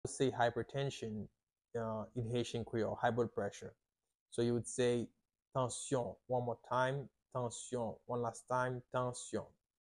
How to say "Hypertension" in Haitian Creole - "Tansyon" pronunciation by a native Haitian Creole teacher
“Tansyon” Pronunciation in Haitian Creole by a native Haitian can be heard in the audio here or in the video below:
How-to-say-Hypertension-in-Haitian-Creole-Tansyon-pronunciation-by-a-native-Haitian-Creole-teacher.mp3